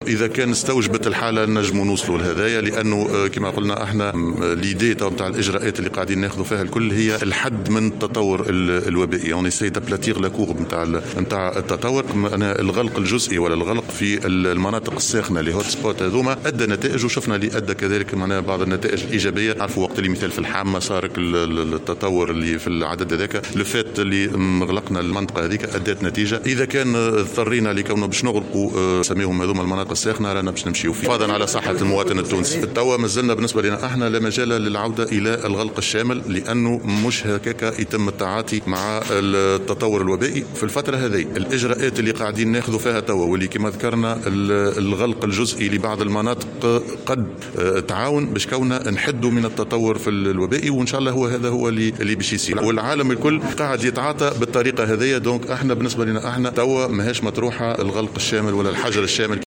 وأضاف في تصريح اليوم بمقر وزارة السياحة على هامش حضوره تكريم عدد من باعثي مشاريع "اقامات ريفية" أن الحجر الصحي الجزئي أعطى نتائج إيجابية ومكّن من السيطرة على حلقات العدوى على غرار ما حصل في الحامّة من ولاية قابس.